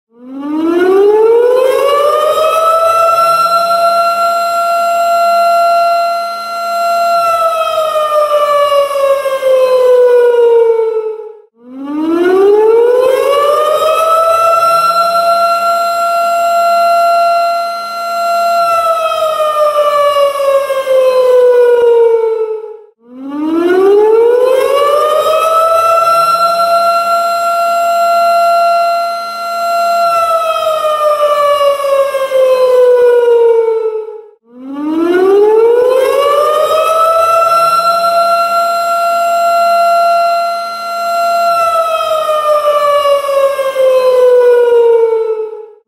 Home Run Horn